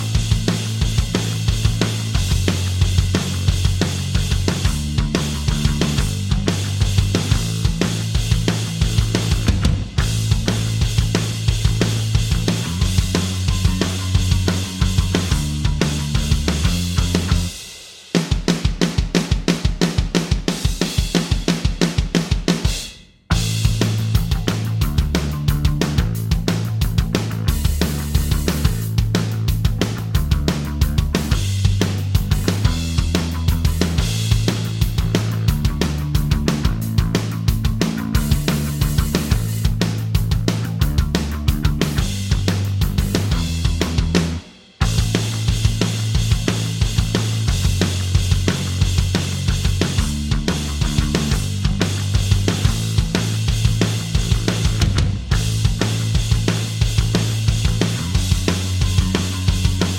Minus Main Guitars For Guitarists 3:02 Buy £1.50